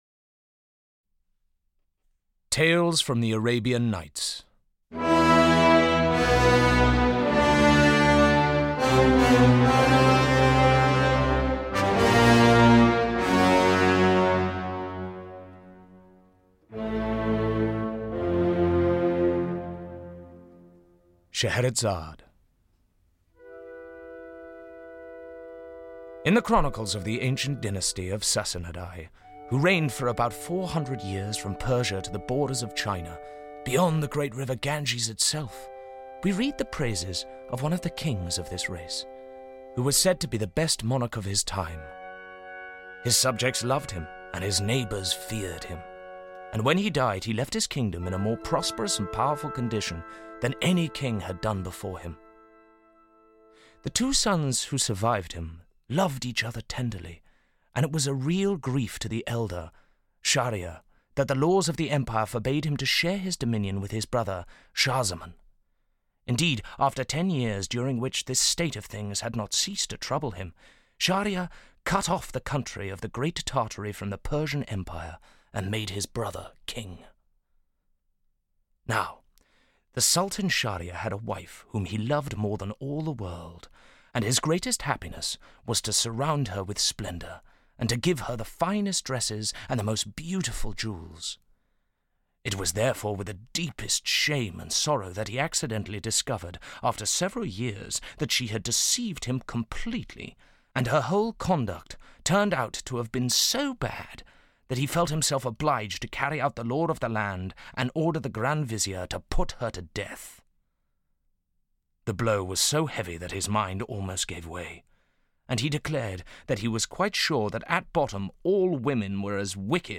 Ukázka z knihy
Sheherezade, night after night, weaves her tales and Aladdin and his Magic Lamp, Sinbad the Sailor, Ali Baba and the Forty Thieves and other tales come alive. The unforgettable music of Rimsky Korsakov sets the scene perfectly.
• InterpretToby Stephens